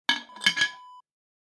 Ceramic Jar Lid Close Wav Sound Effect #2
Description: Closing sound of a ceramic jar lid
Properties: 48.000 kHz 24-bit Stereo
A beep sound is embedded in the audio preview file but it is not present in the high resolution downloadable wav file.
ceramic-jar-close-preview-2.mp3